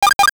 NOTIFICATION_8bit_12_mono.wav